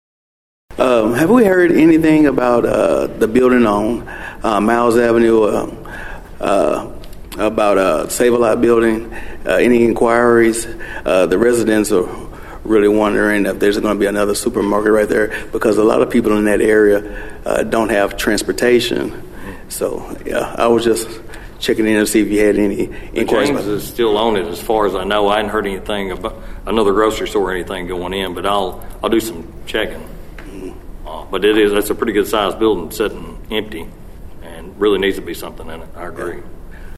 An inquiry was made about a vacant building in Union City during this week’s City Council meeting.
Councilman Patrick Smith asked City Manager Johnny McTurner about the building on Nailing Drive.(AUDIO)